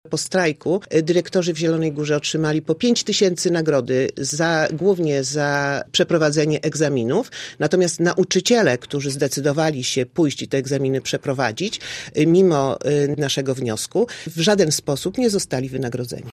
Cała rozmowa do wysłuchania na stronie Radia Zielona Góra.